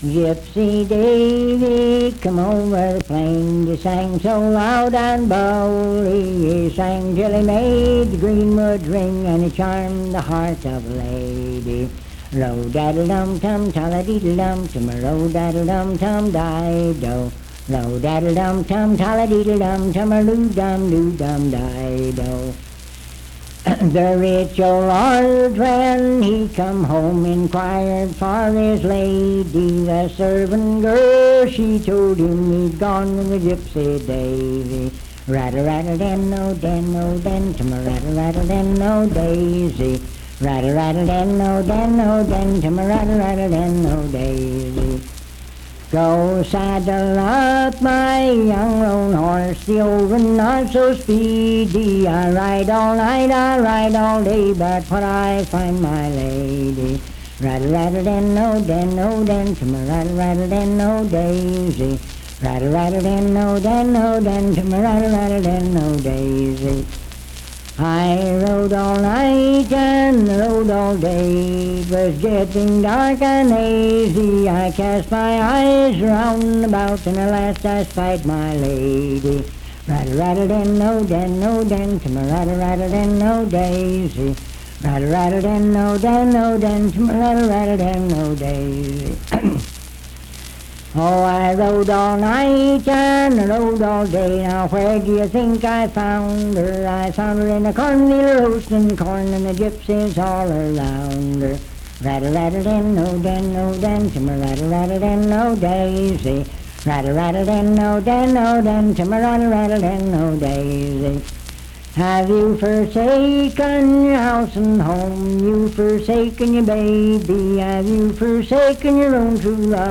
Unaccompanied vocal music
Verse-refrain 8d(4w/R).
Performed in Sandyville, Jackson County, WV.
Voice (sung)